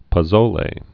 (pə-zōlā, -sō-) or po·zo·le (-zō-)